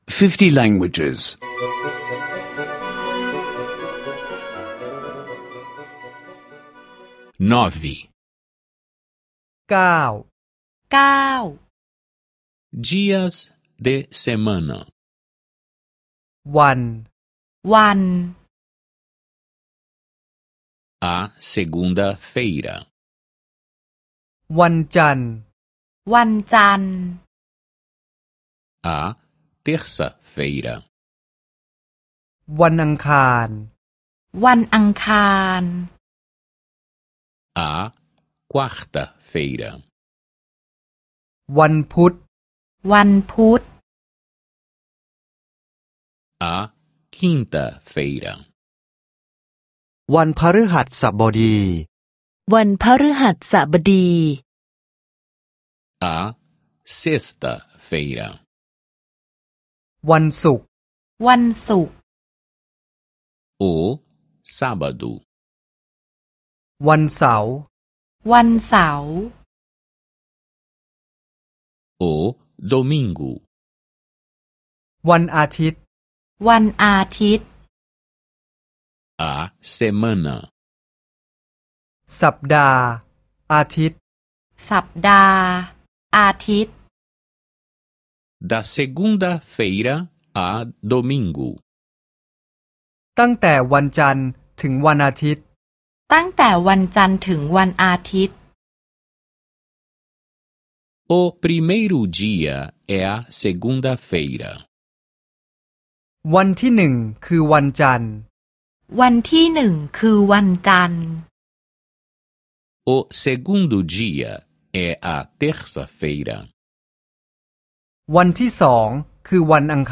Aulas de tailandês em áudio — download grátis